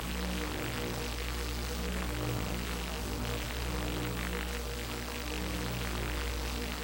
sfx_hand_attraction_loop.wav